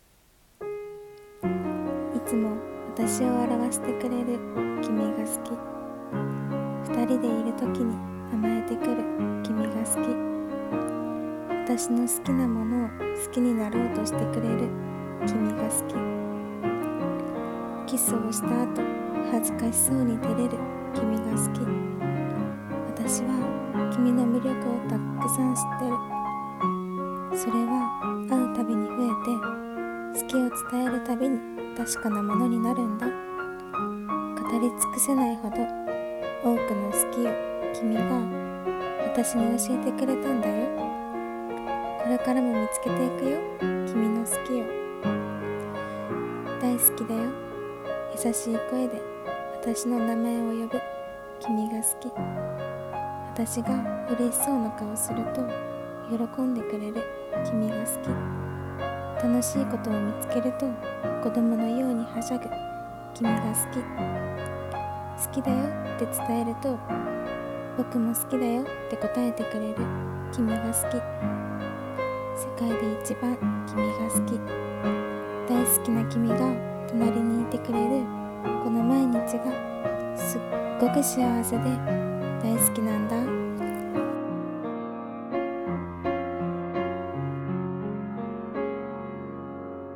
声劇【好き】※恋愛声劇